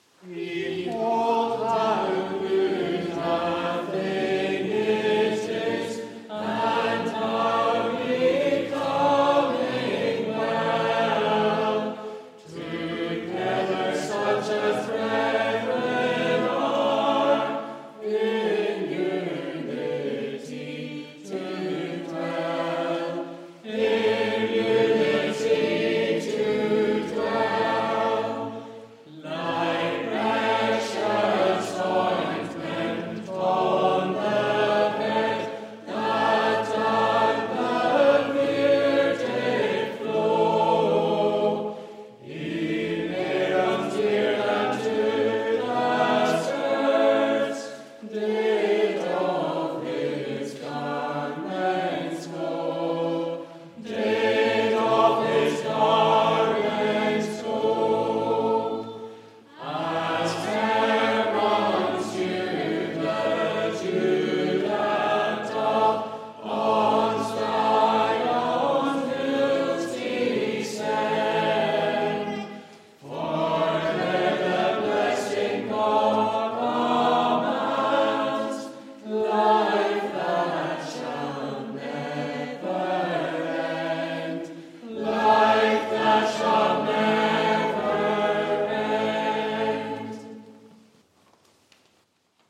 Congregational psalm singing from our evening service, held in All Souls Church, Invergowrie, on 14 April 2024.